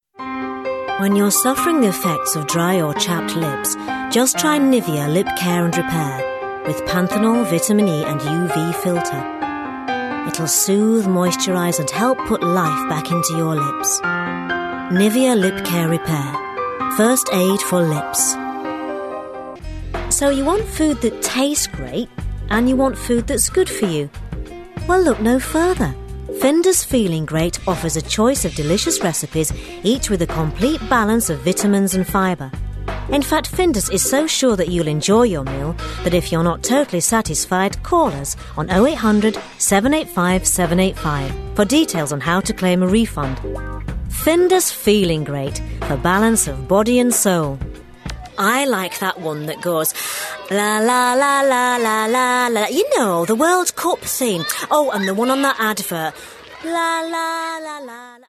30s-40s. Female. Studio. Yorkshire.